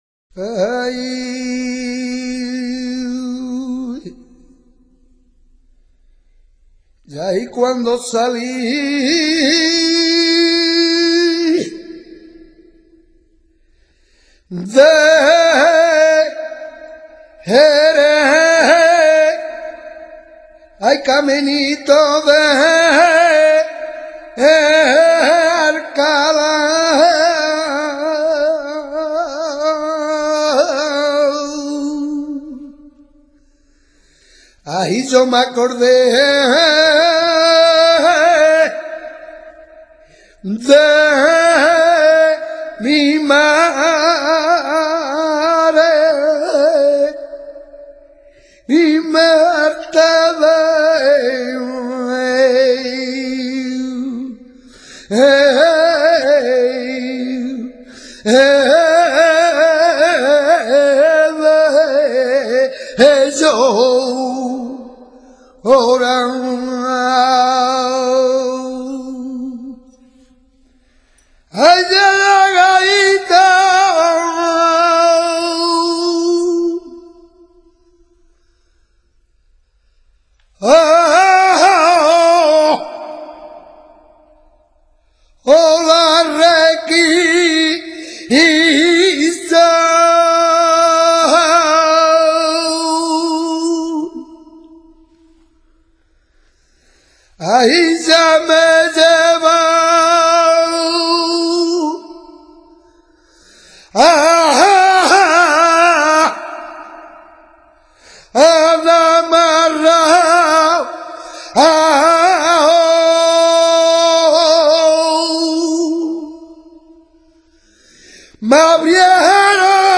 Como tal ton� es un cante sin acompa�amiento, lastimoso, monocorde, de tercios arrastrados, que suele terminar con un largo quej�o. // 2.
Escuchar un Martinete
martinete.mp3